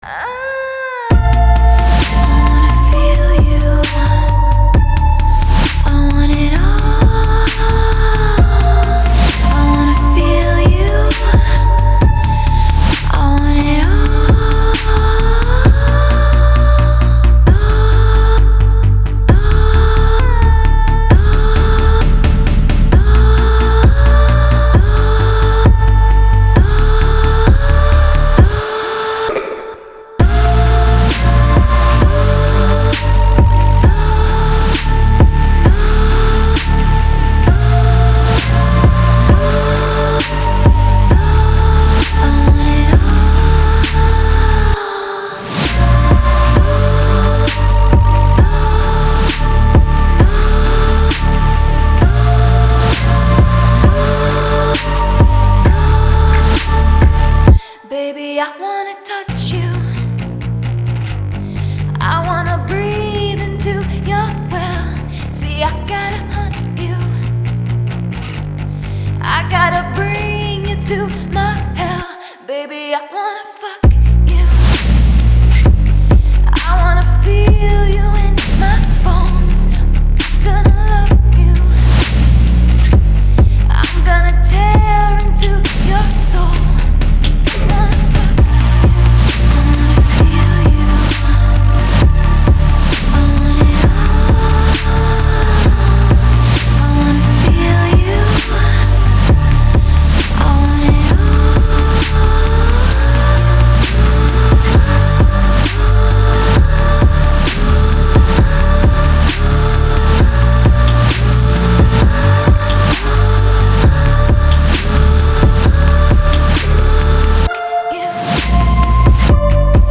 это завораживающая комбинация электроники и инди-попа
пронизан чувством жажды и стремления